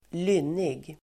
Ladda ner uttalet
Uttal: [²l'yn:ig]